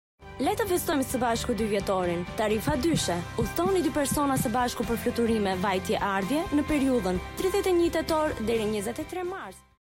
Professionelle Sprecher und Sprecherinnen
Weiblich